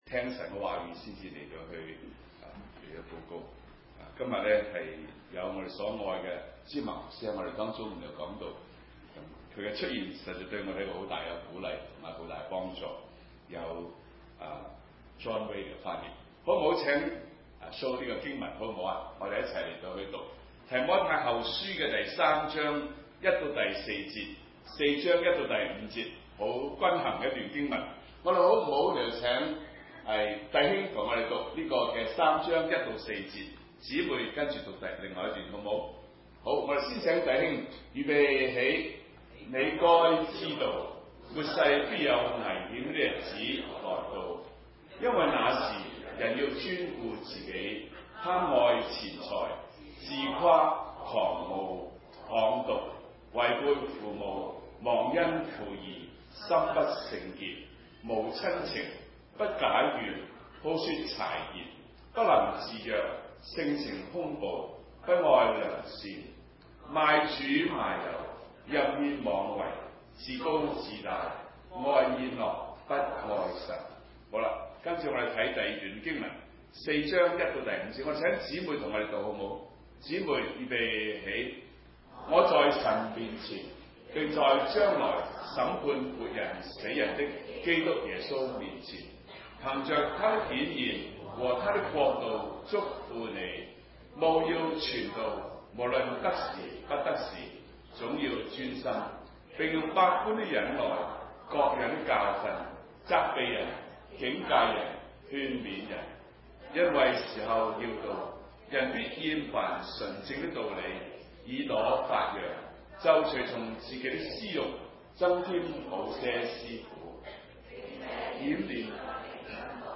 RCCC Sermon On the Net